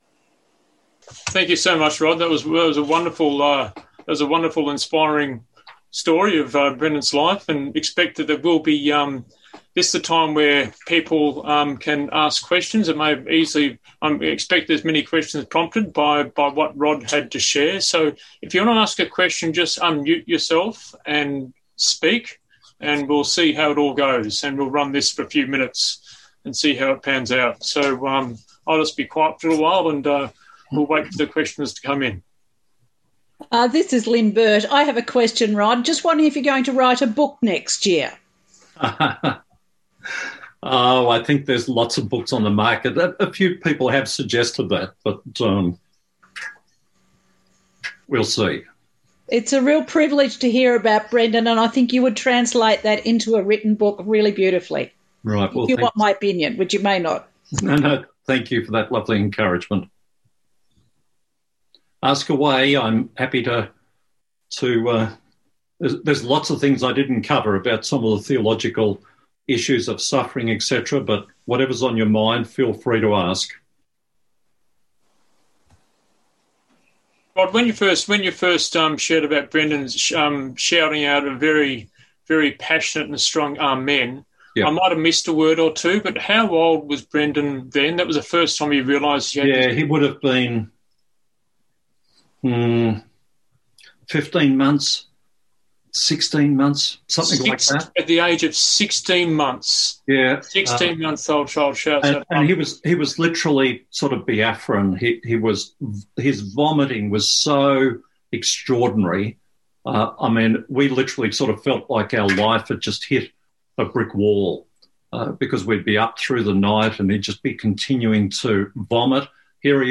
Zoom Men’s Event - Broken in Body, Booming in the Spirit - Q and A